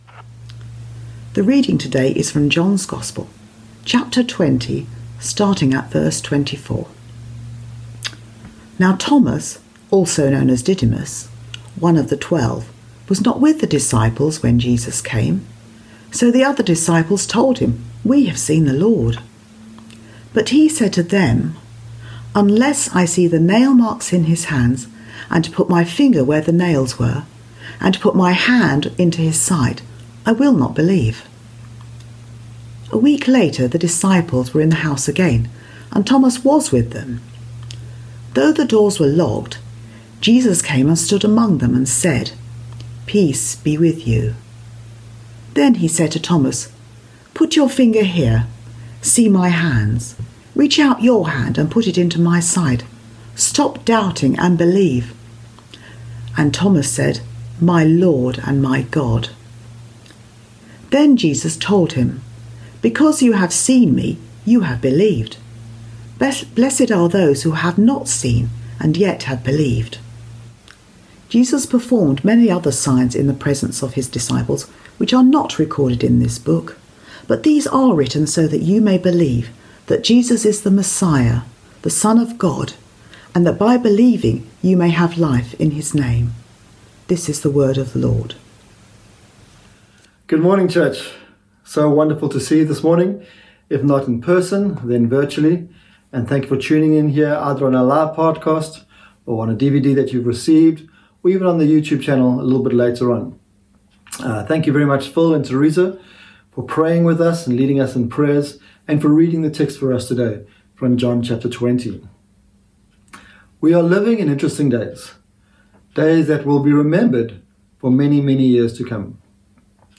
John 20:24-31 Service Type: Streaming Download Files Notes Topics